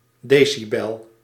Ääntäminen
Ääntäminen Paris: IPA: [de.si.bɛl] France (Île-de-France): IPA: /de.si.bɛl/ Haettu sana löytyi näillä lähdekielillä: ranska Käännös Konteksti Ääninäyte Substantiivit 1. decibel {m} akustiikka Suku: m .